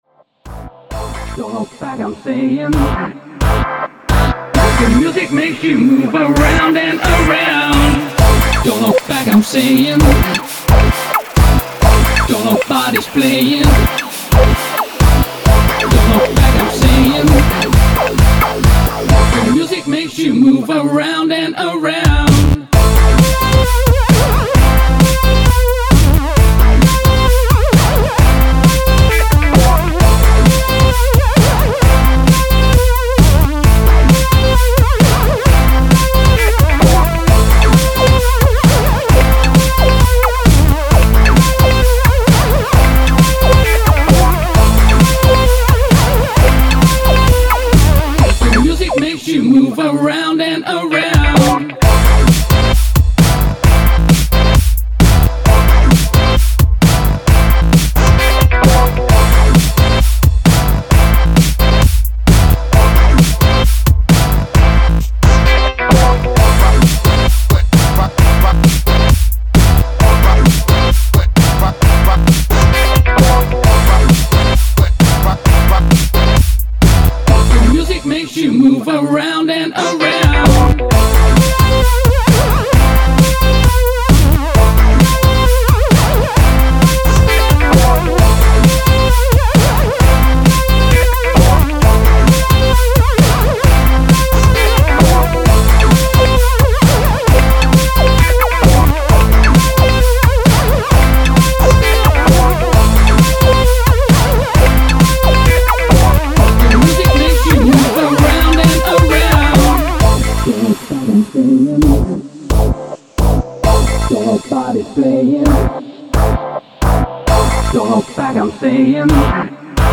Funky Breaks